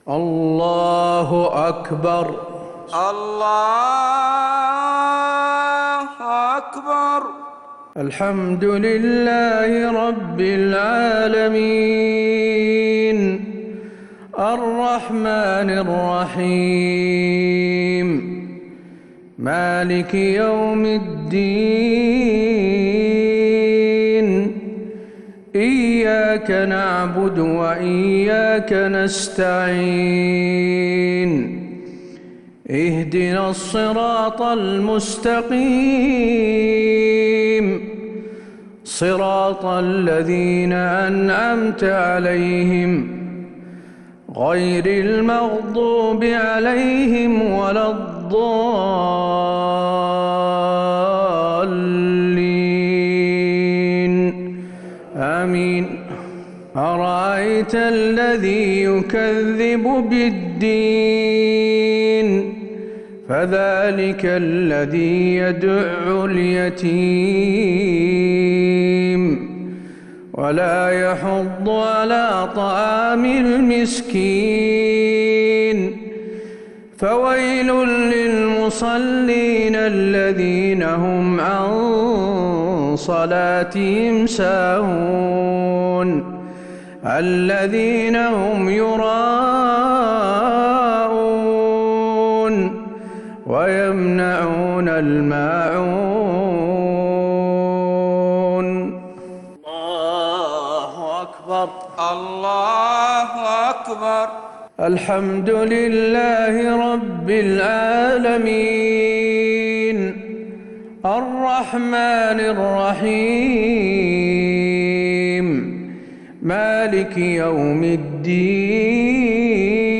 صلاة المغرب للشيخ حسين آل الشيخ 22 صفر 1442 هـ
تِلَاوَات الْحَرَمَيْن .